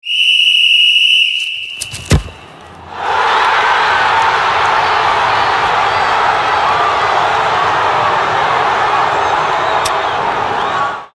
Свисток, удар и гол